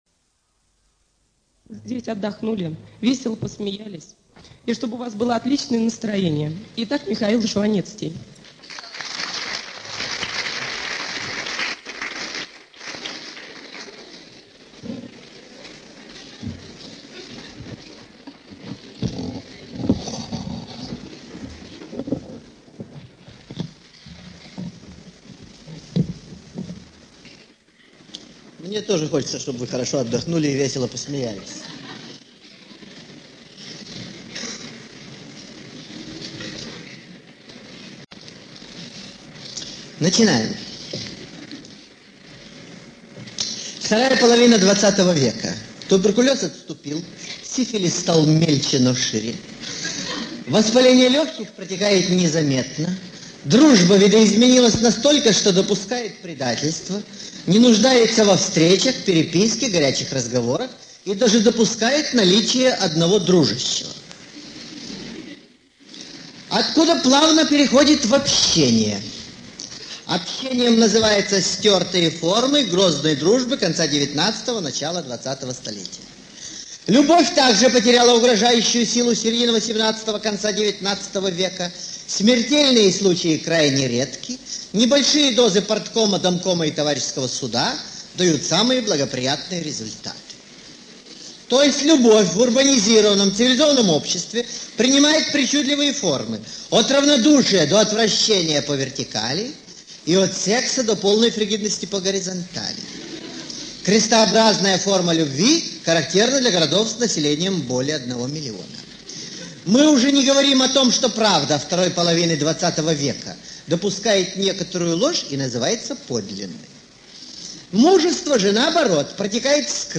ЧитаетАвтор